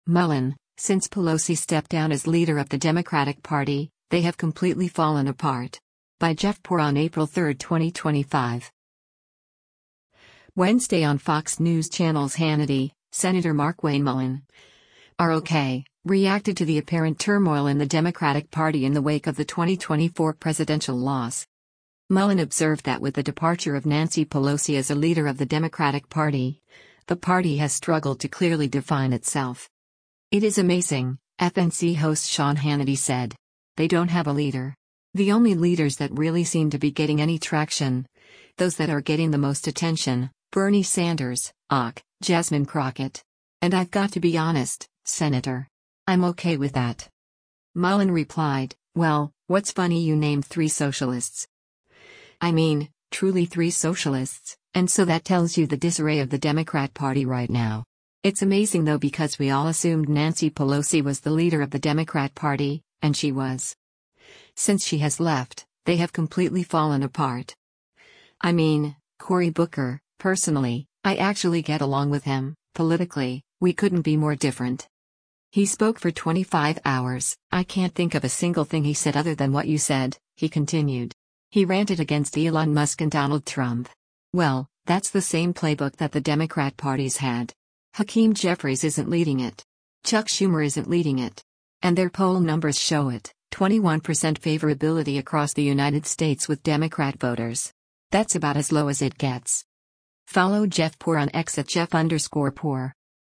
Wednesday on Fox News Channel’s “Hannity,” Sen. Markwayne Mullin (R-OK) reacted to the apparent turmoil in the Democratic Party in the wake of the 2024 presidential loss.